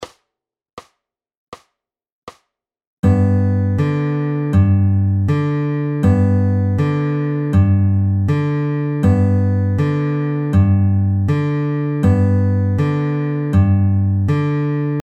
A pinch is when we play a melody note (with the fingers) at the same time as playing a bass note (with the thumb).
ADD A PINCH ON FIRST BEAT